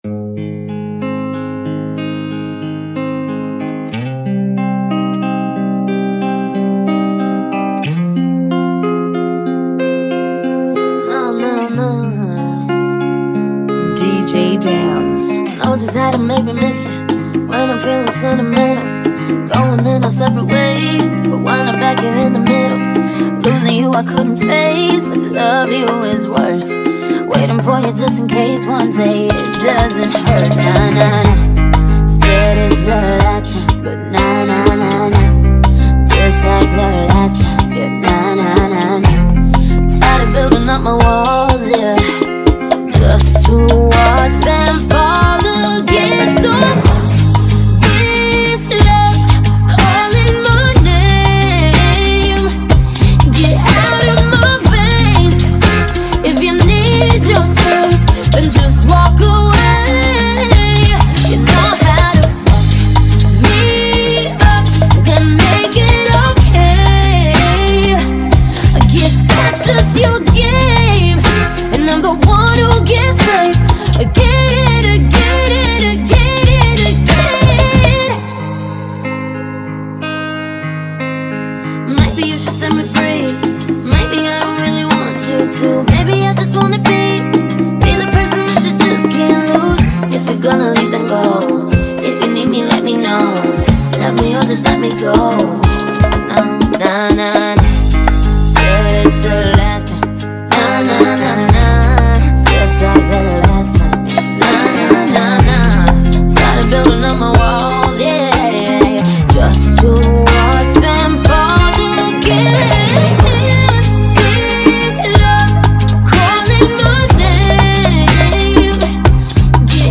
123 BPM
Genre: Bachata Remix